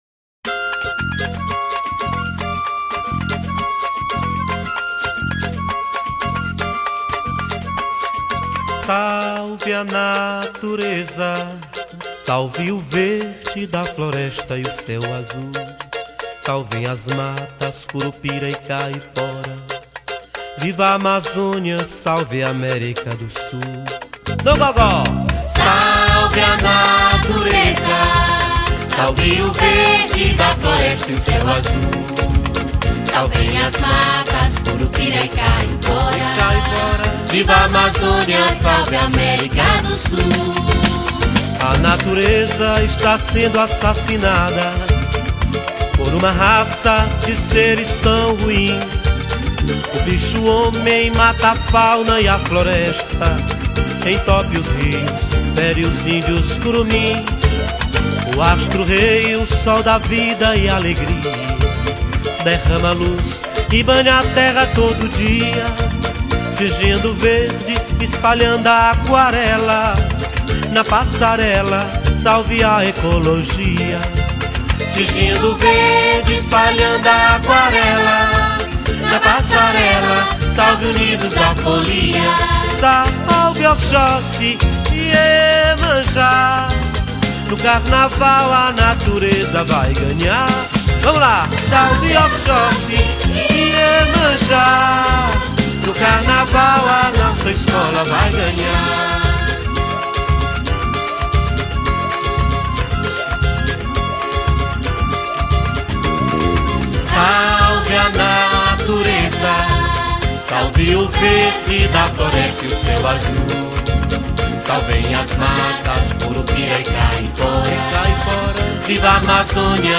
3097   03:36:00   Faixa: 10    Folclore Piauiense